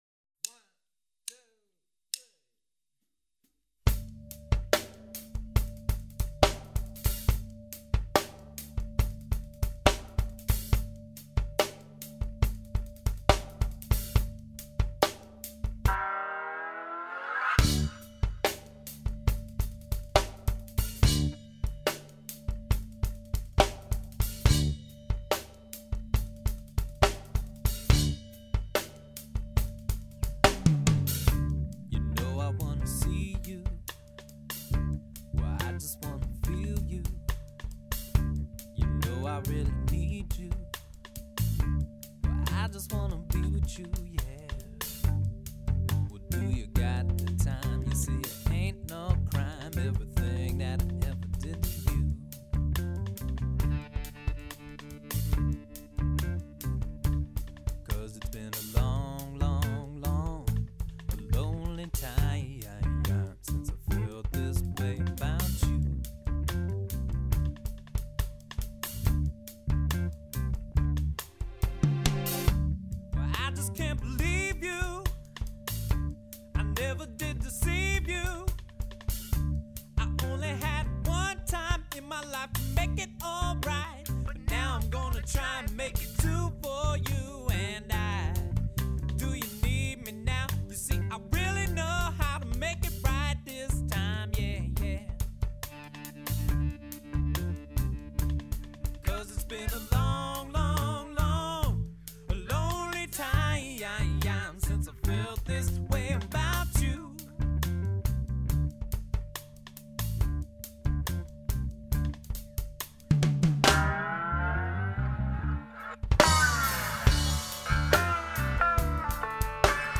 Lead Vox and Percussion
Bass
Drums and Percussion
It was a steel guitar that he said dated back to the 20's.